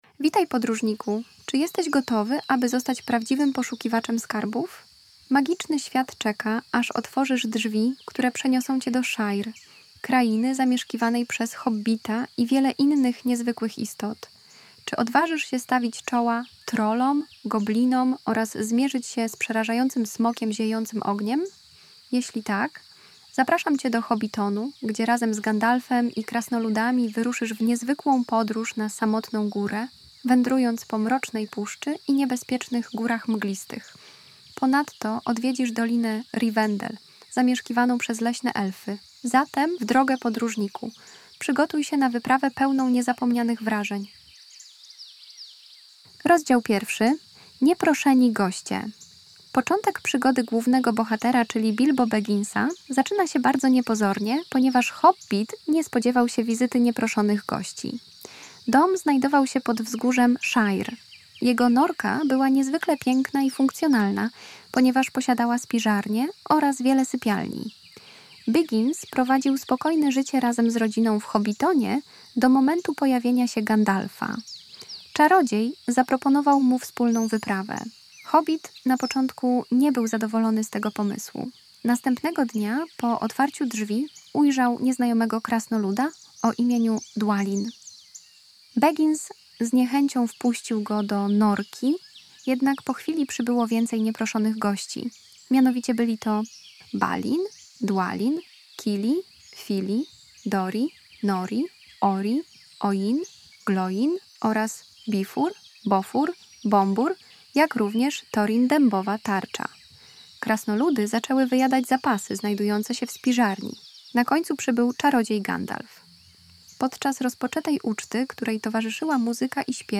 Hobbit - 3 w 1- Prezentacja multimedialna, audiobook, e -book